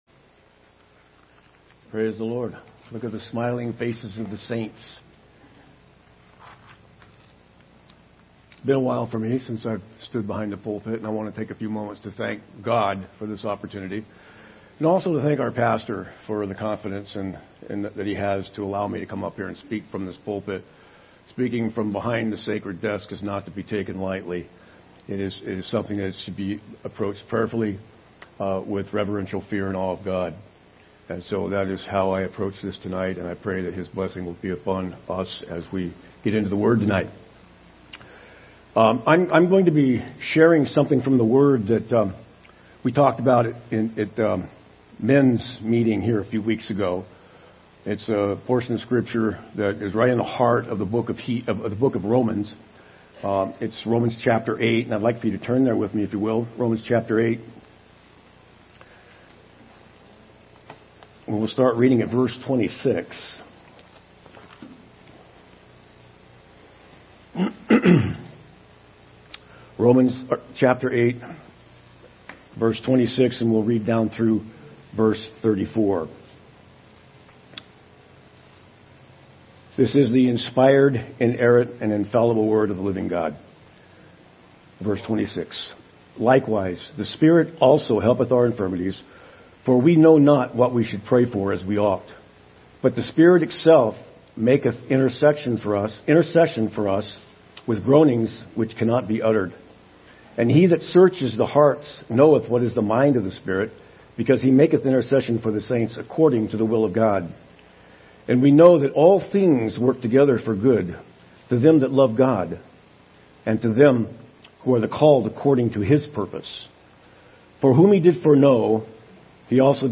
Midweek Service September 7, 2022
Recent Sermons